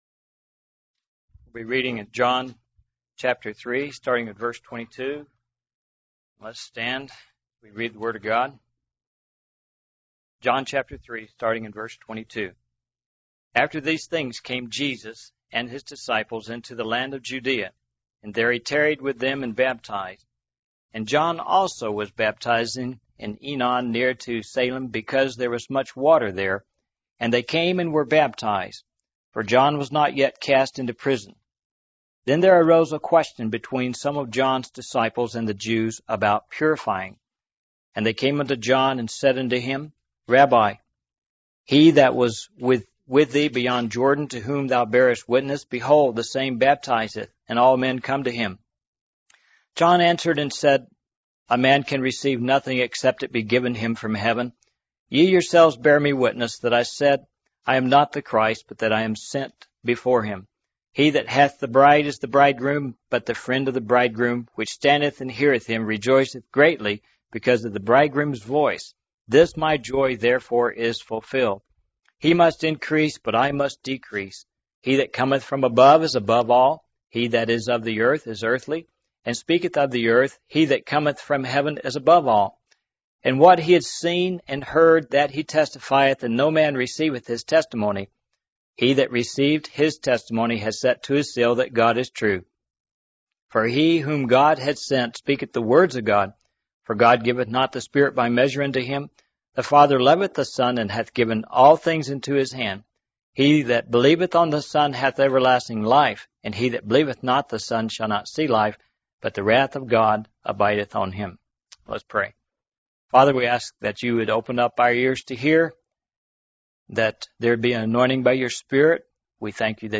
In this sermon, the preacher starts by emphasizing the importance of receiving and hiding God's words in our hearts. He encourages the congregation to seek wisdom and understanding, comparing it to searching for hidden treasure.